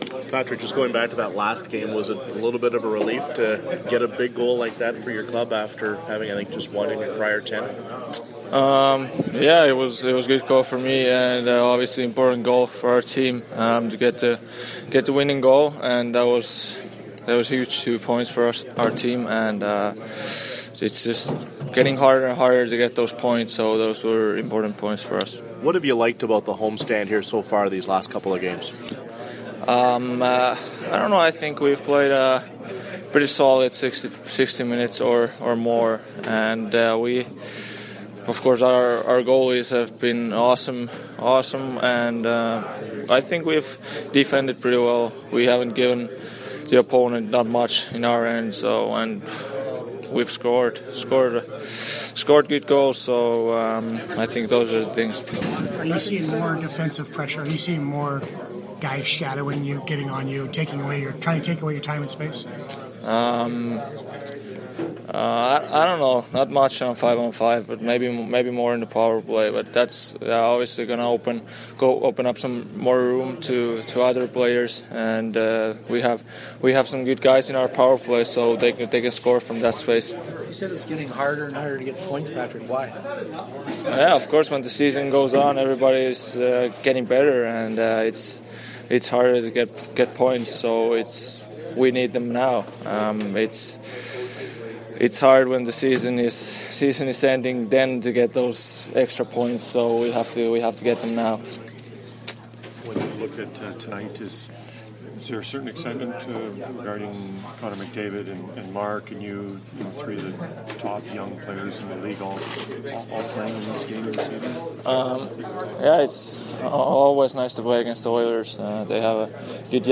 Player pre-game audio:
All audio courtesy of TSN 1290 Winnipeg.